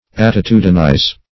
Attitudinize \At`ti*tu"di*nize\, v. i.